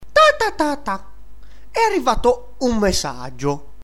Descarga de Sonidos mp3 Gratis: comic 1.
baz-comic.mp3